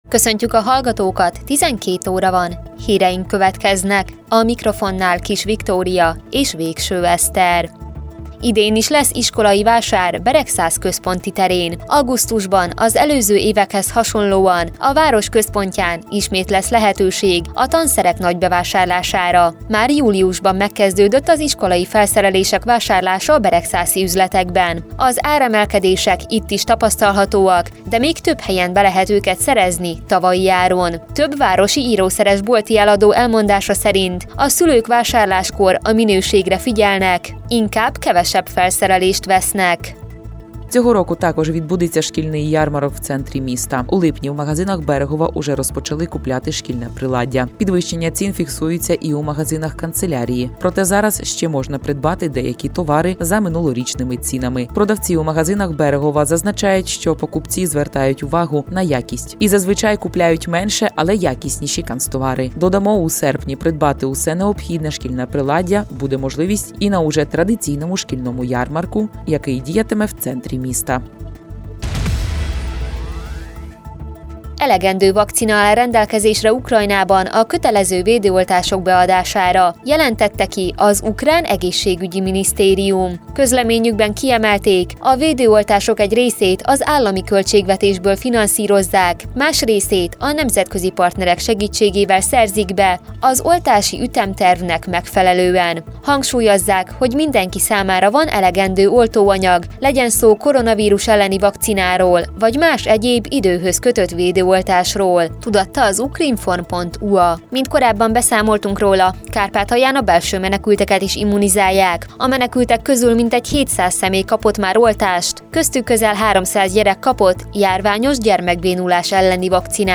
2022. július 18. hétfő, déli hírek